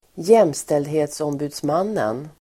Folkets service: Jämställdhetsombudsmannen (JämO) Jämställdhetsombudsmannen (JämO) pronomen, Equal Opportunities Ombudsman Uttal: [²j'em:steldhe:tsombu:dsman:en ²j'em:o:] Definition: ombudsman som medverkar till att främja jämställdhet i arbetslivet